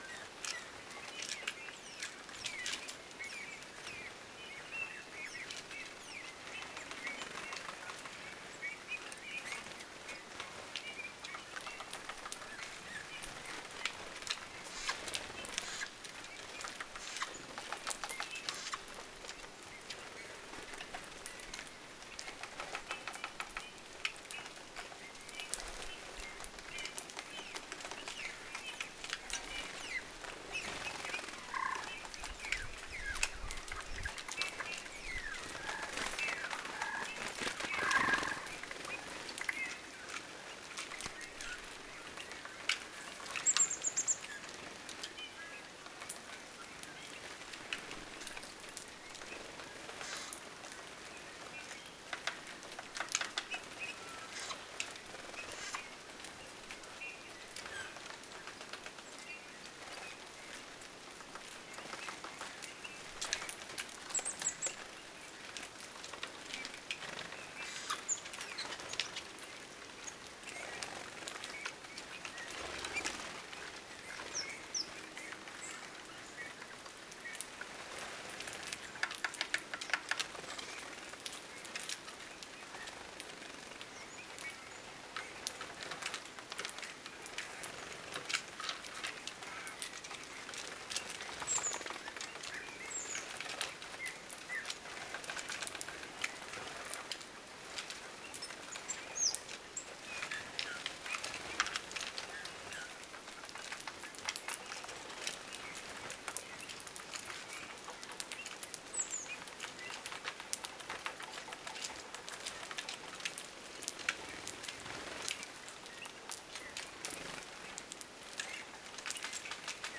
erikoisia pähkinähakin ääniä,
hiljaisia
erikoista_hiljaista_pahkinahakin_hyrailua_tai_ehka_kevatlaulun_alkuvirittelya.mp3